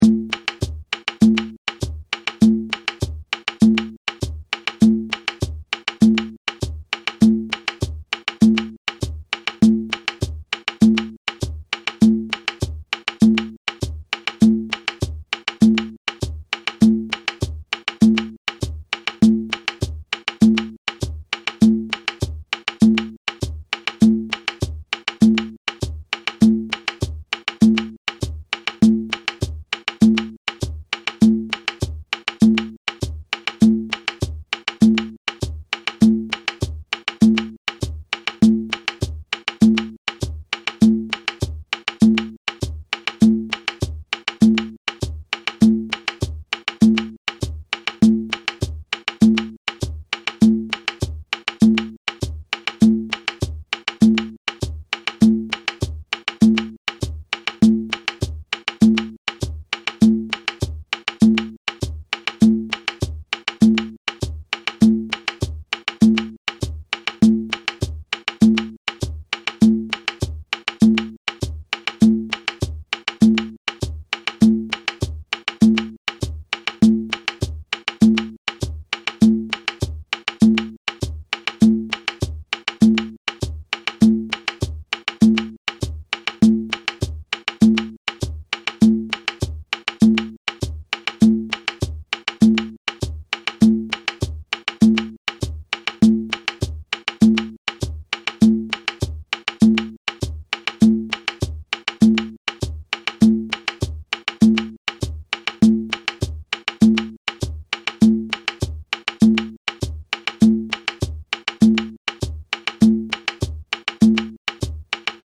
WEST AFRICAN SAMBA – This playful, informal dance rhythm combines West African and Afro-Brazilian rhythmic concepts.
low part audio (with shekeré)